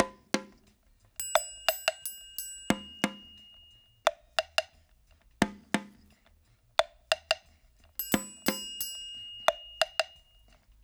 88-PERC1.wav